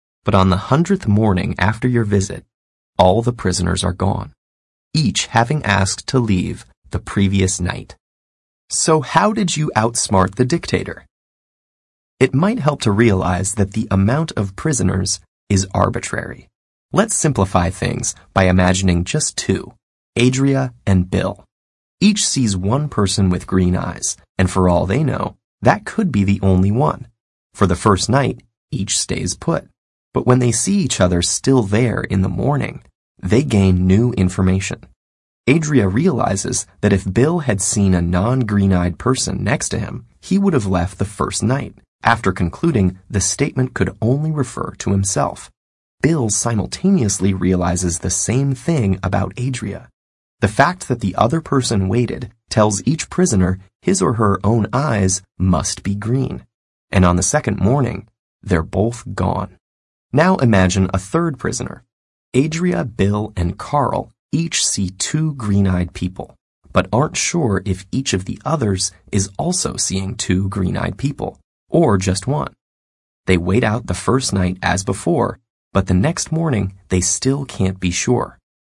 TED演讲:著名的绿眼逻辑难题(2) 听力文件下载—在线英语听力室